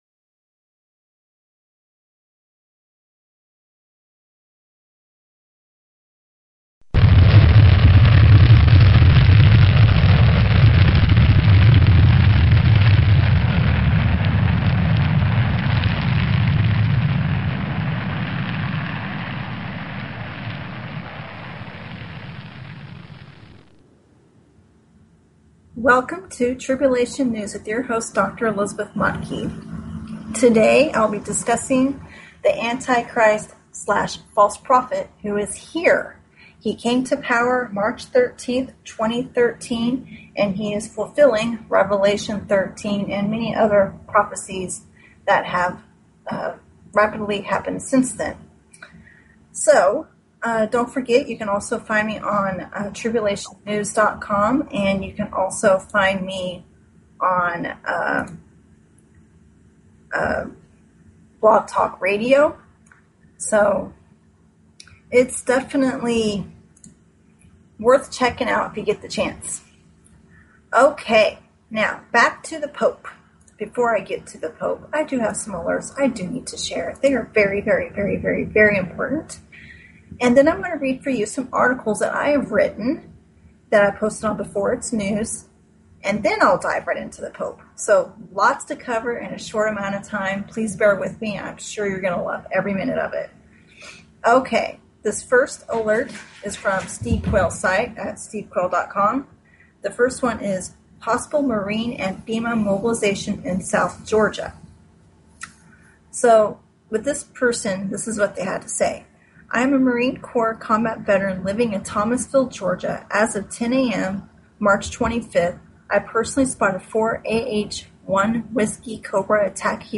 Talk Show Episode, Audio Podcast, Tribulation_News and Courtesy of BBS Radio on , show guests , about , categorized as
Tribulation News is all about current events, and how Bible Prophecy is getting fulfilled. This broadcast will also include a Bible study, and it is an online ministry to reach as many people as possible during these end times.